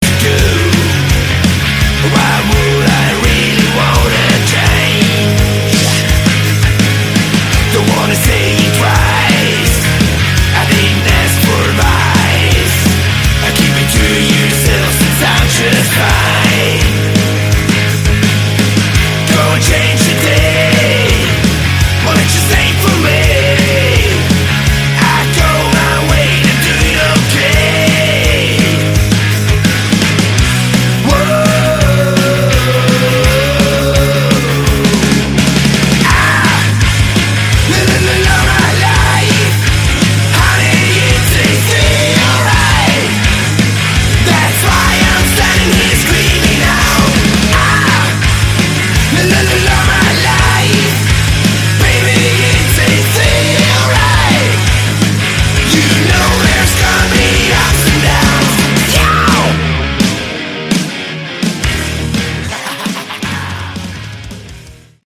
Category: Hard Rock
vocals, guitar
lead guitar